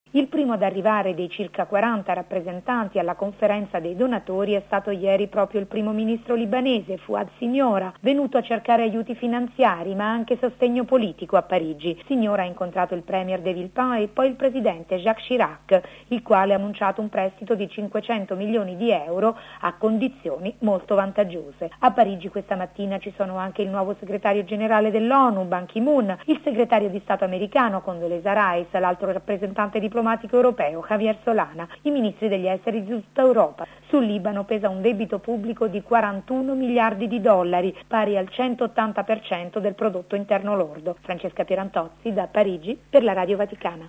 Da Parigi, il servizio